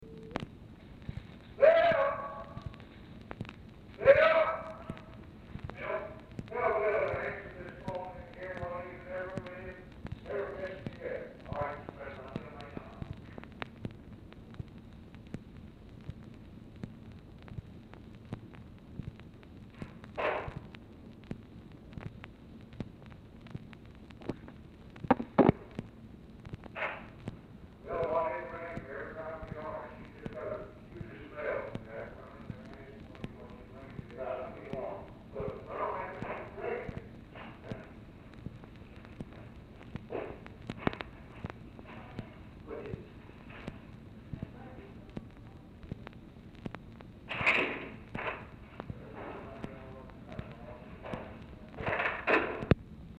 Telephone conversation
Format Dictation belt
Oval Office or unknown location